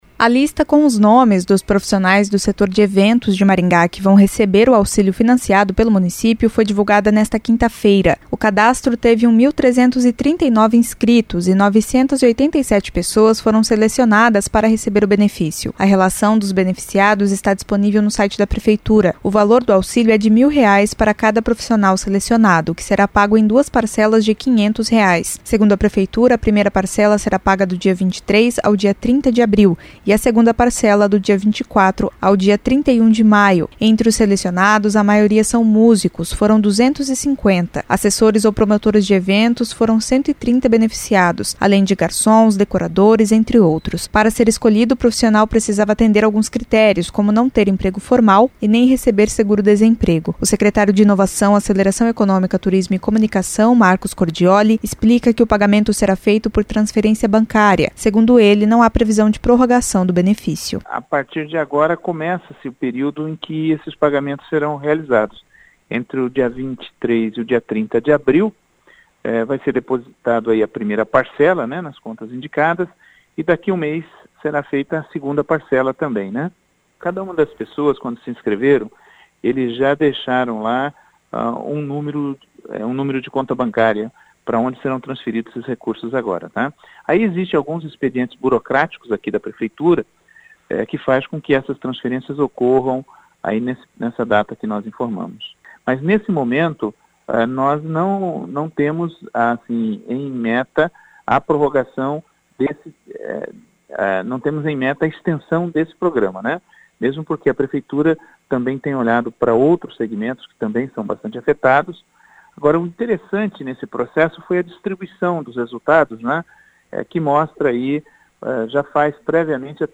O secretário de Inovação, Aceleração Econômica, Turismo e Comunicação (Siacom), Marcos Cordiolli, explica que o pagamento será feito por transferência bancária.